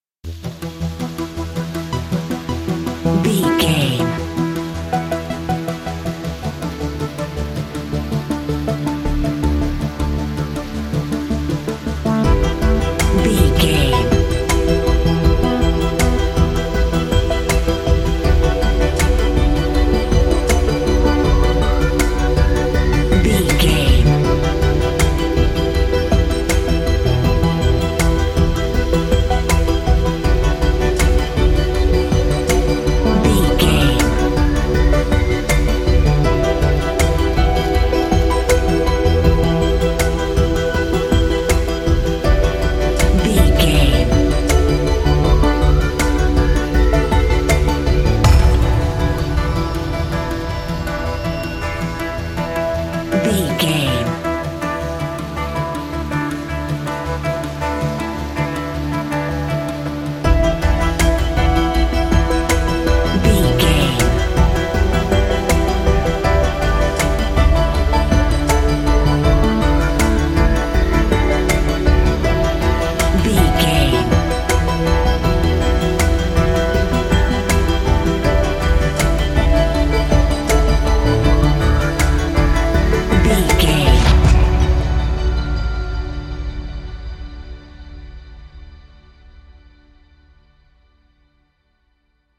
In-crescendo
Thriller
Aeolian/Minor
Slow
drum machine
synthesiser